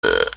burp2.wav